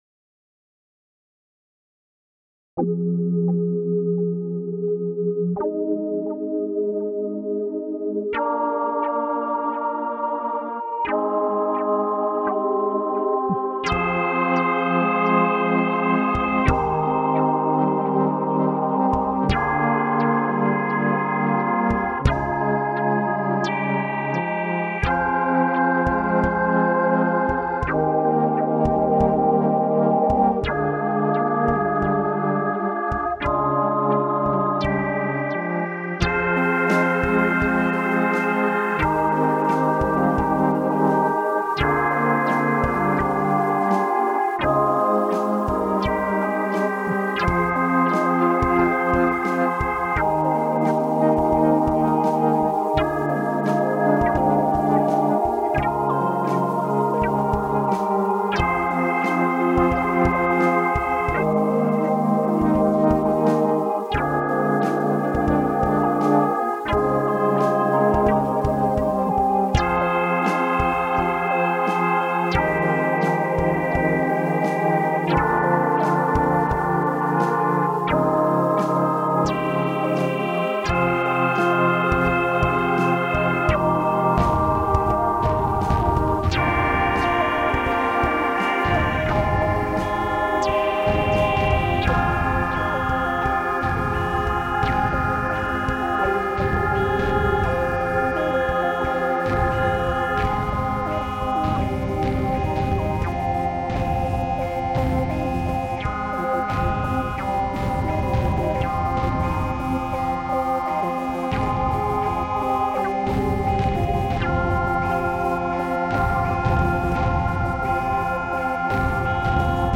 electronic_music space_music